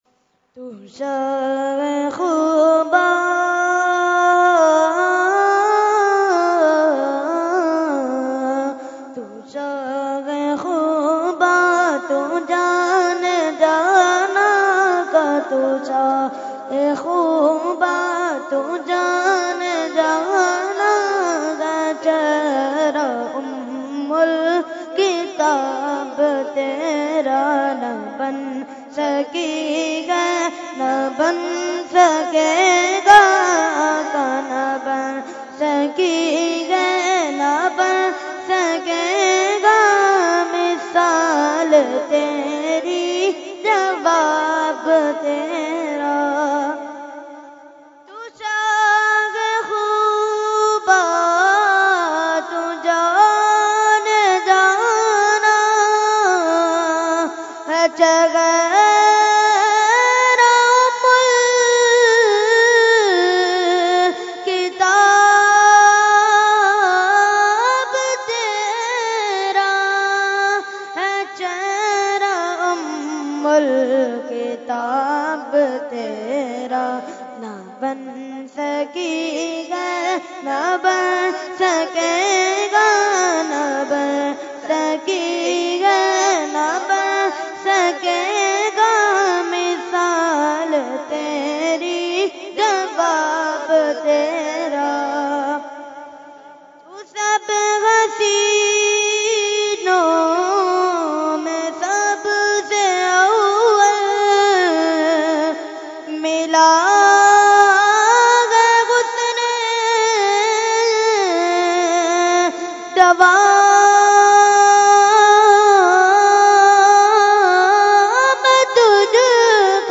Category : Naat | Language : UrduEvent : Urs Qutbe Rabbani 2019